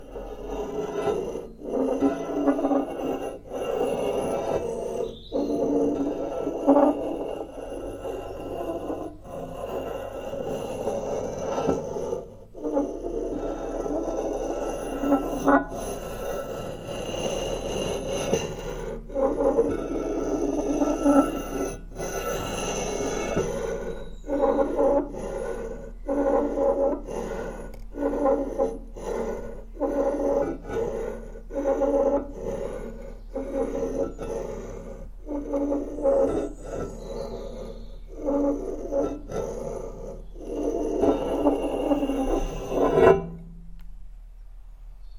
Distressing hatchet sound
Duration - 45s Environment - This is recorded in a large shed with a corrugated iron roof. It has an open space at the side in which other sounds can also be detected at times. Description - This is using the hatchet and using the edge straight down directly on iron it create an awkward uncomfortable sound for the listener.